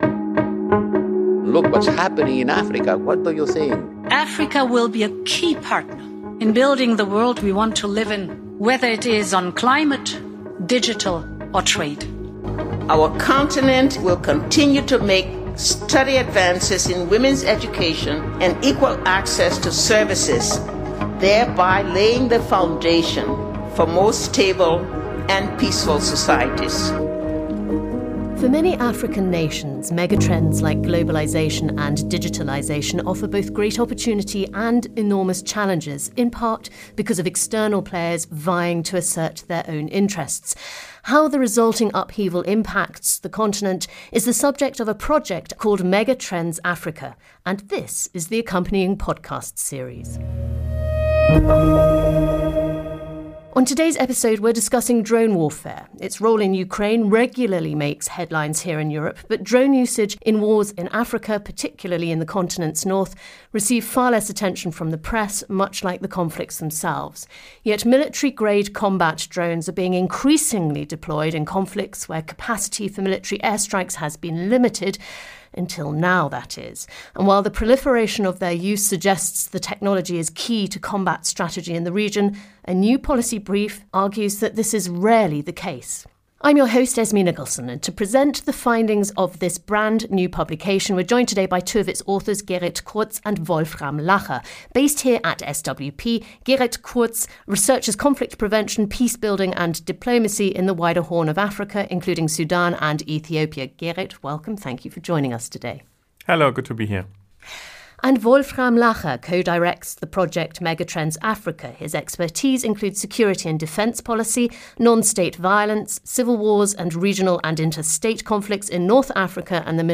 Im SWP-Podcast diskutieren Wissenschaftlerinnen und Wissenschaftler der Stiftung Wissenschaft und Politik, vereinzelt auch mit externen Gästen, Themen der internationalen Politik, die politische Entscheiderinnen und Entscheider zurzeit beschäftigen – oder beschäftigen sollten.